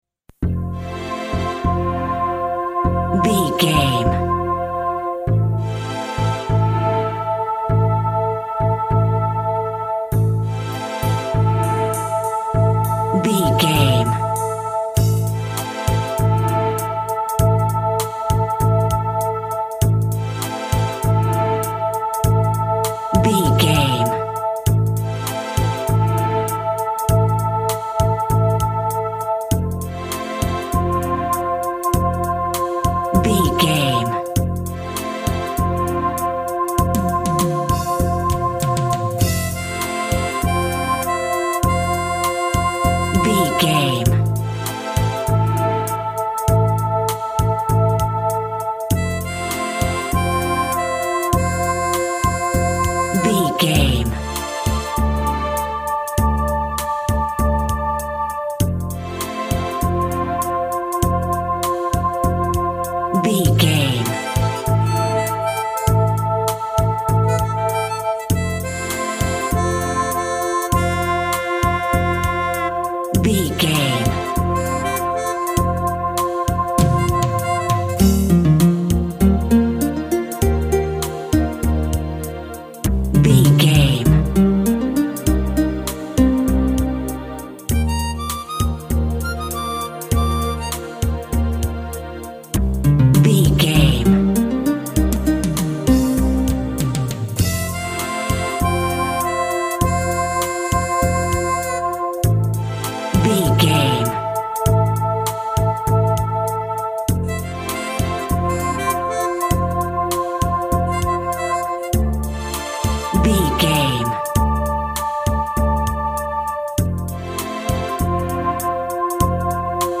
Eighties Fusion Pop Ballad.
Ionian/Major
Slow
dreamy
tranquil
smooth
melancholy
drums
synthesiser
bass guitar
strings
acoustic guitar
electronic
synth bass
synth lead